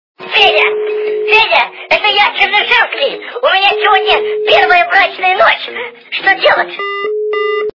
» Звуки » Смешные » Федя, Федя. - Это я Чернышевский. У меня сегодня первая брачная ночь, что делать?